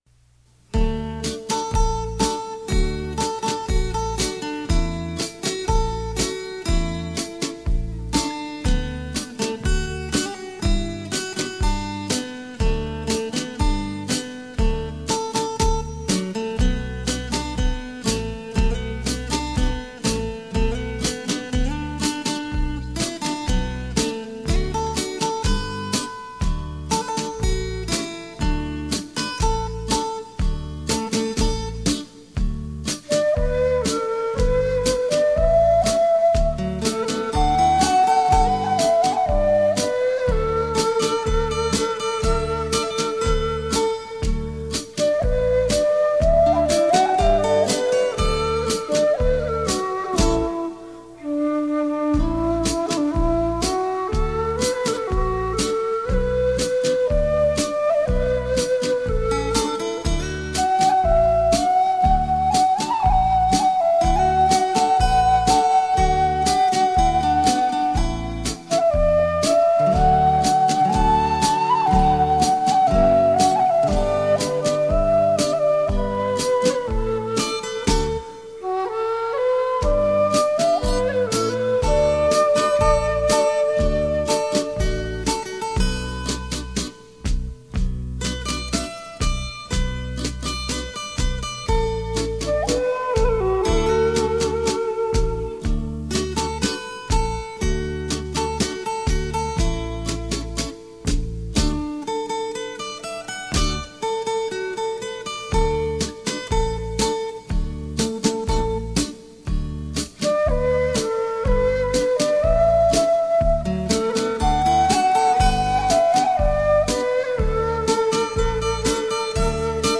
立体效果 环绕身历声 超魅力出击 全新风格精心制作